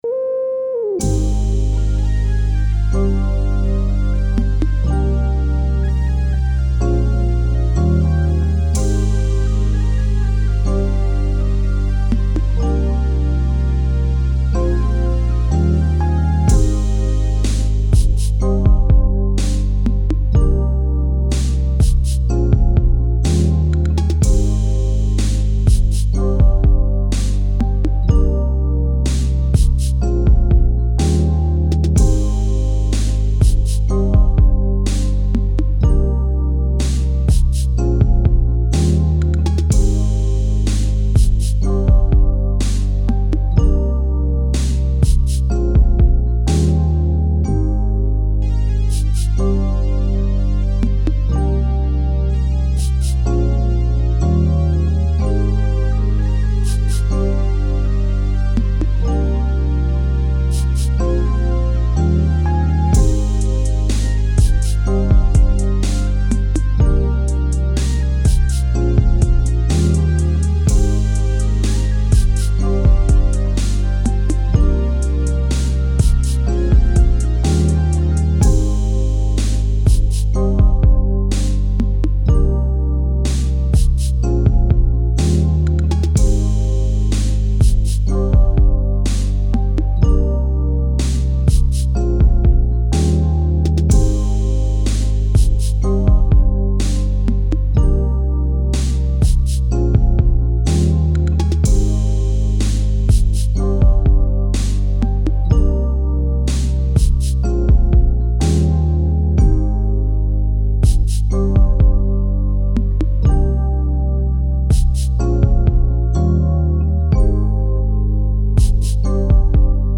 R&B, 90s
Ebmaj